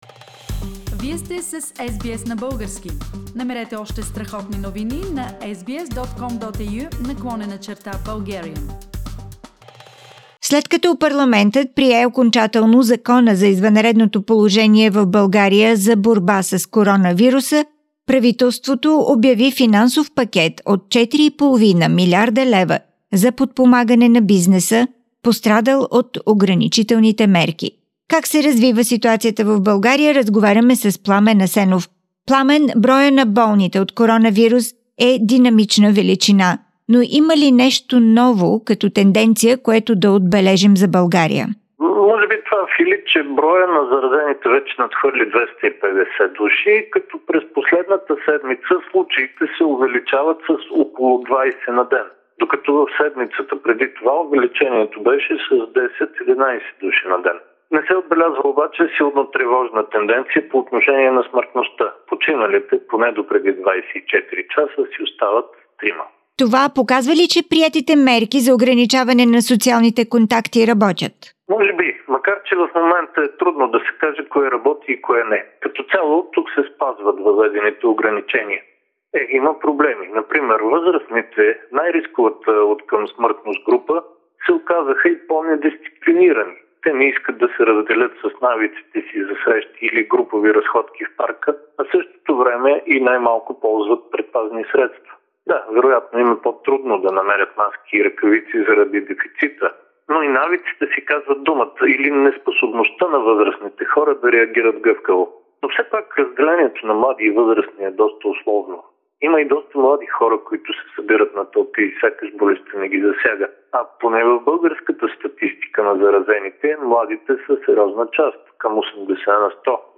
Political Analysis